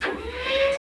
SERVO SE09.wav